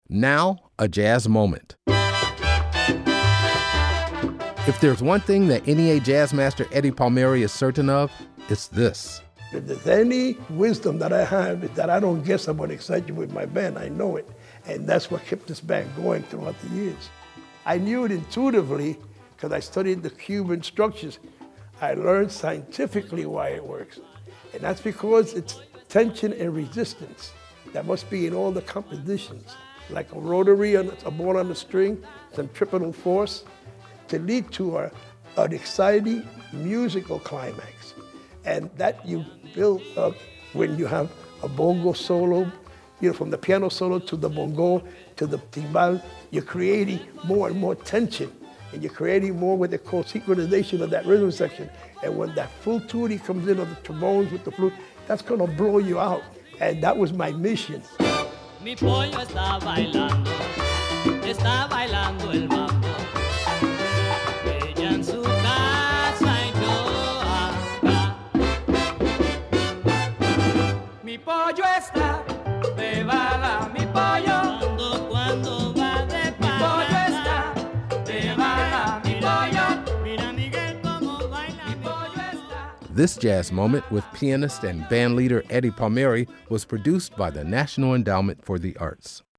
Excerpt of "Mi Pollo” from the album, La Perfecta composed and   performed by Eddie Palmieri, used courtesy of FANIA music and by permission of The Palmieri Organization (BMI).